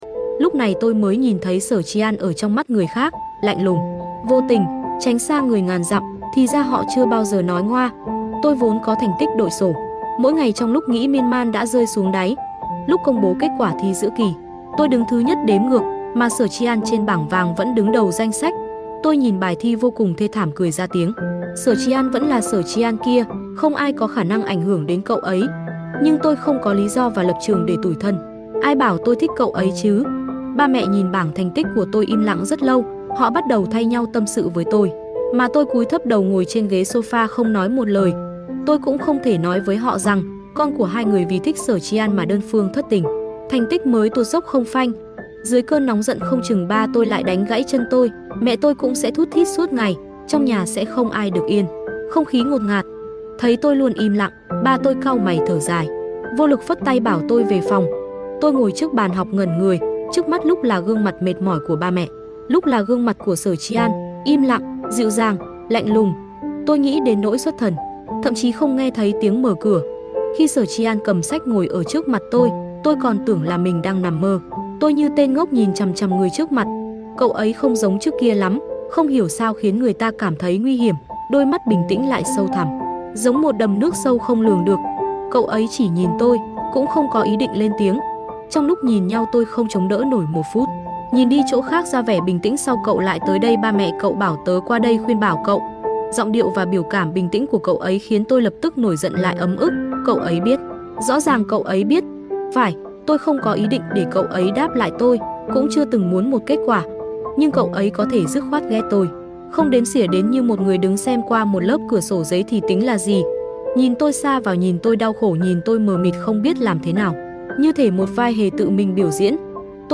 TRUYỆN AUDIO | Mai Táng Sound Effects Free Download